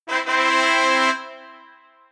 fanfare.mp3